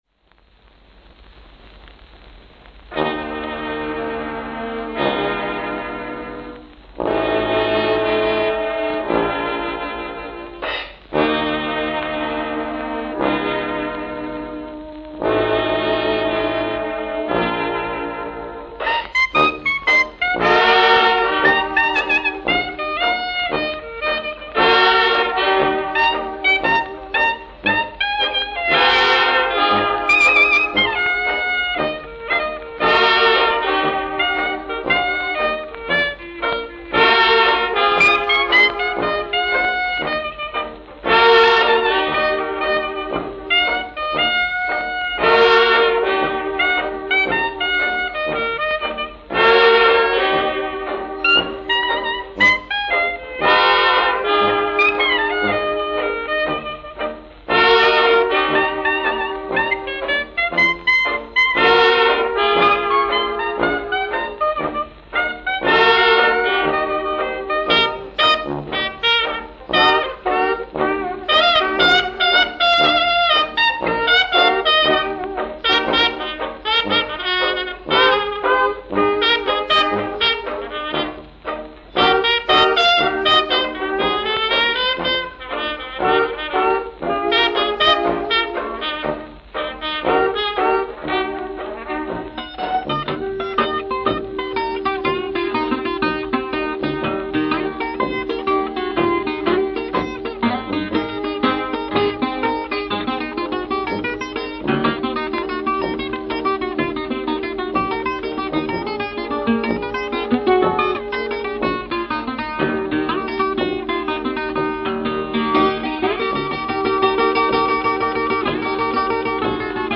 New York, New York New York, New York